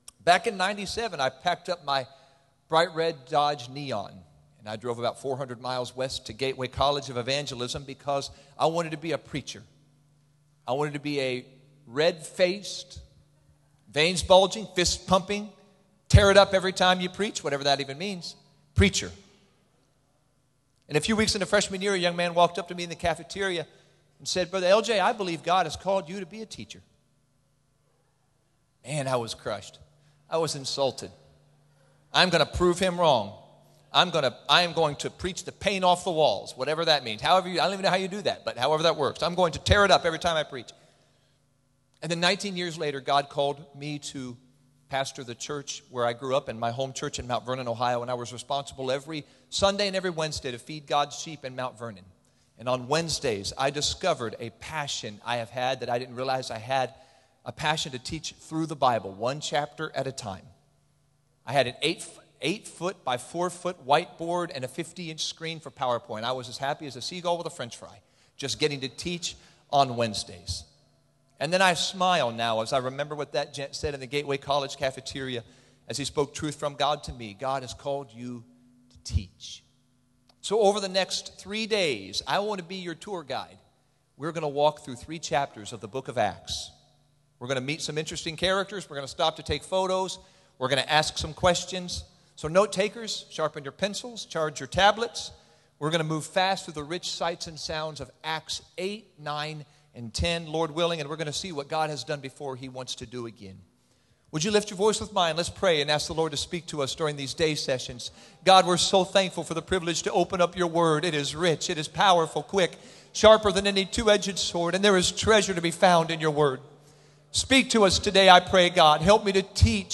Sermon Archive | Illinois District
Camp Meeting 25 (Wednesday AM)